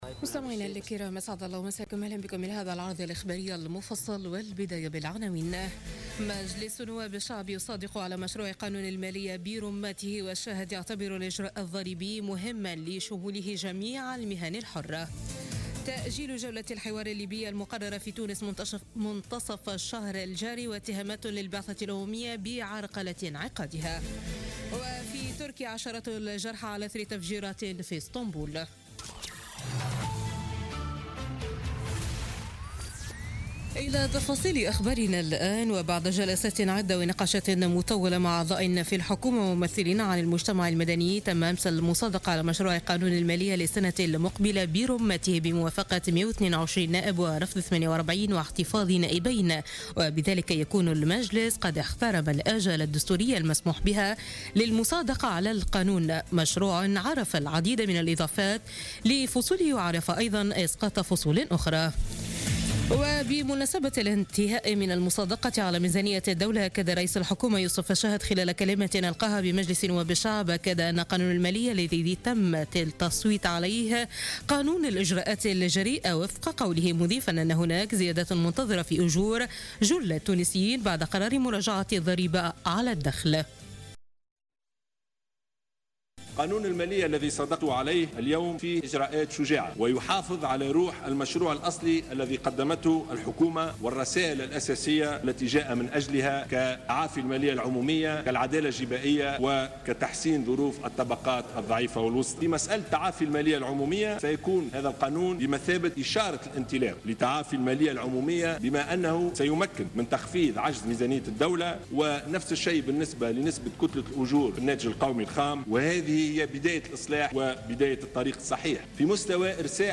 نشرة أخبار منتصف الليل ليوم الأحد 11 ديسمبر 2016